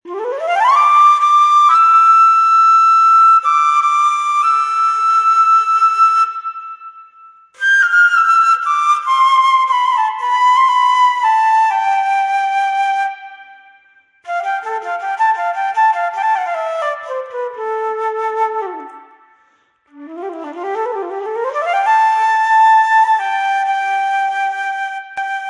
莫尔斯代码
描述：1940年开播的一个名为“向南极探险队致敬”的广播节目。